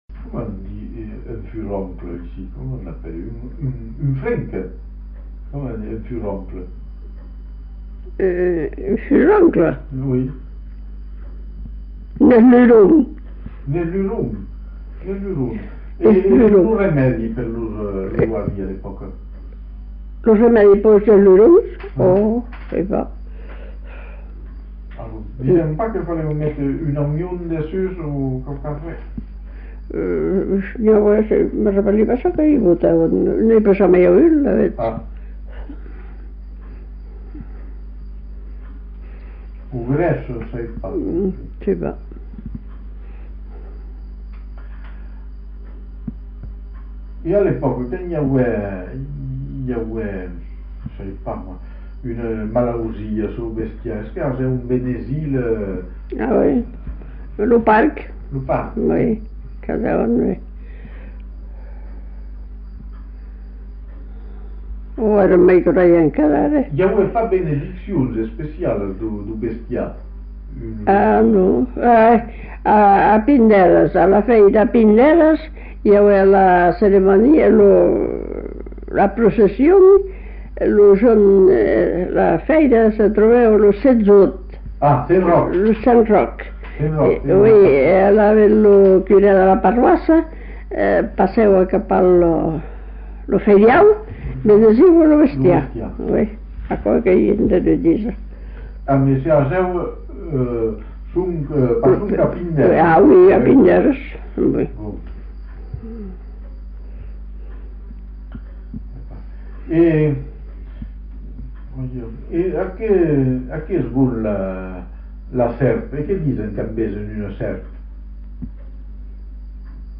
Lieu : Sauméjan
Genre : témoignage thématique